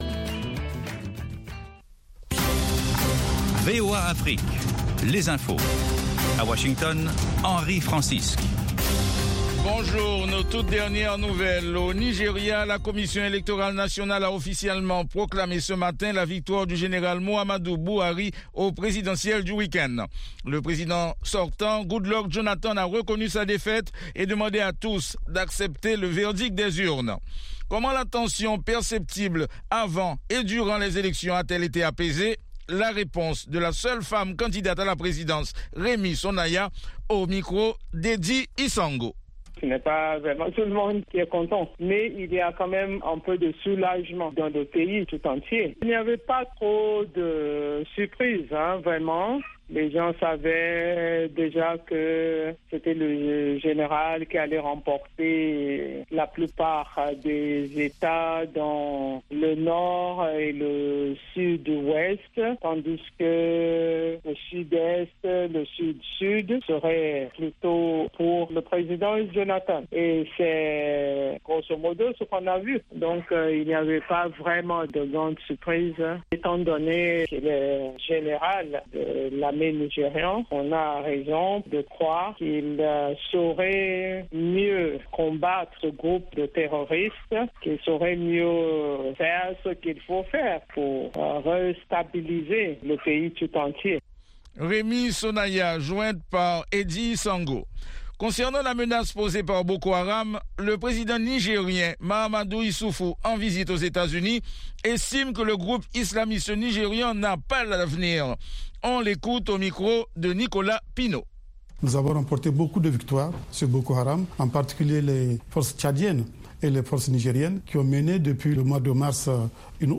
Bulletin
5min Newscast